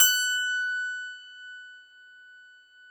53s-pno19-F4.aif